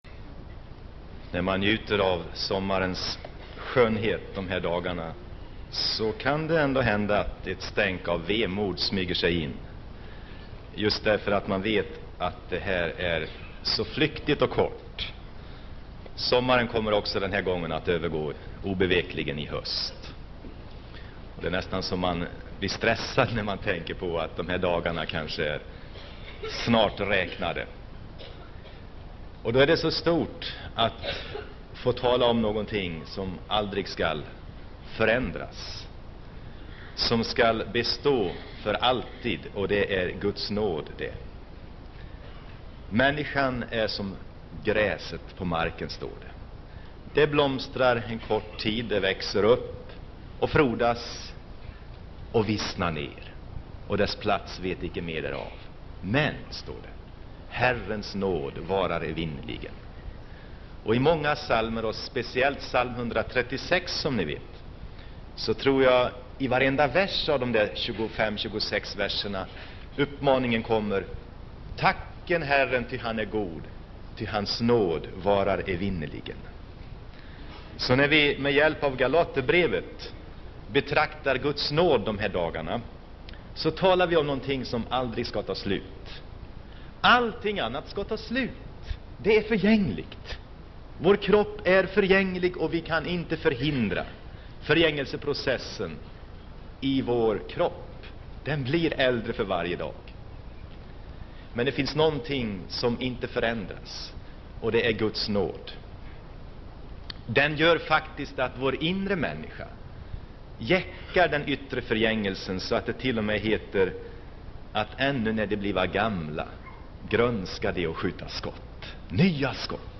Inspelad på Hönökonferensen, Hönö 7 juli 1983.